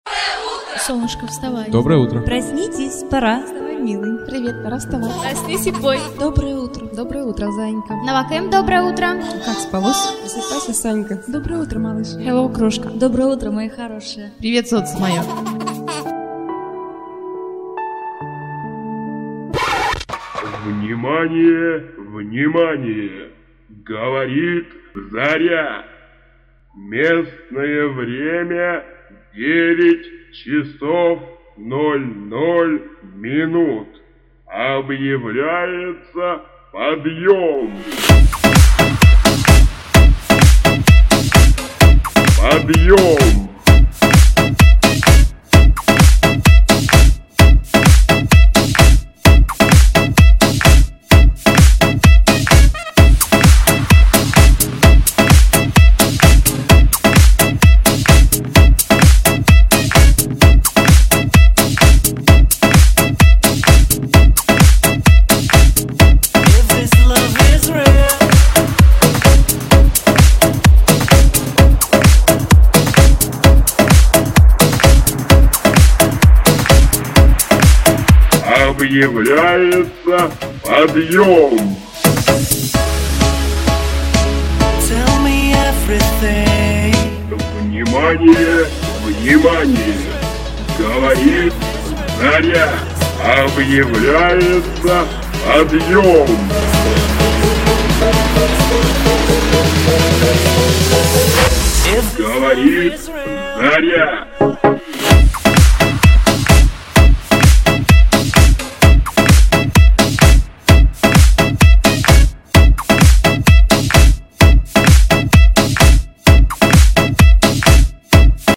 budil_nik__solnyshko_prosypaysya_dobroe_utro_vremya_9_00.mp3